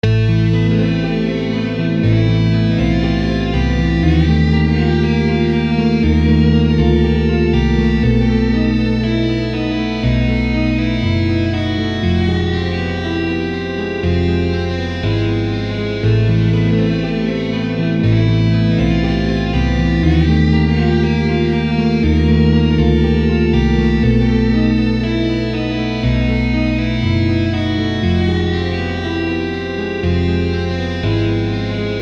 Tämän kuukauden soolon ydinajatuksena on luoda soolo tai melodia atonaalisen sointukierron päälle.
Rakennetaan tämänkertaista sooloa varten neljän soinnun kierto, jossa soinnut ovat teoreettisesti etäällä toisistaan.
Valitsin soinnut hyvin sattumanvaraisesti ja ne ovat Am, Eb, B ja Fm.
Viimeisessä Fm-soinnussa on lisäsävelinä b sekä e. Vaikkakaan sointukierrossa ei ole varsinaista sävellajia, lisäsävelvalinnoilla nojaamme vahvasti Am-sävellajiin, joka tuo yhtenäisyyttä sen kuulokuvaan.
kuukauden-soolo-elokuu.mp3